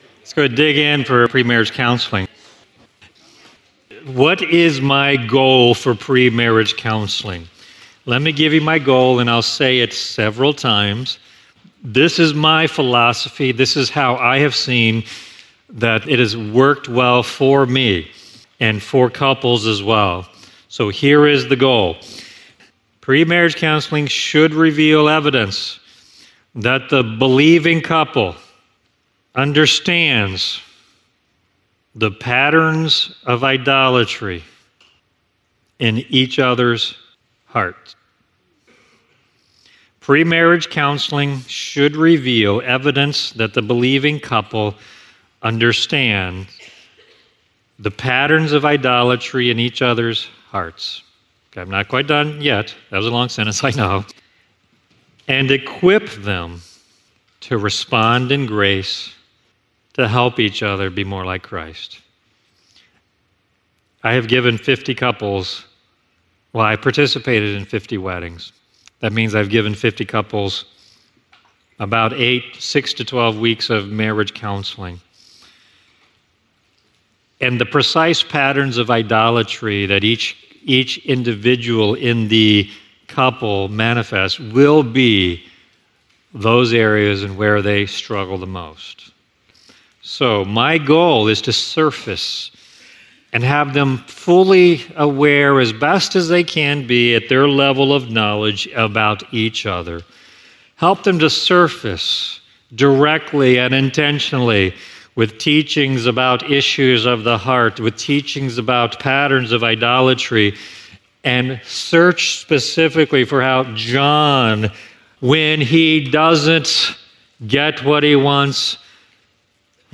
This is a session from a Biblical Counseling Training Conference hosted by Faith Church in Lafayette, Indiana.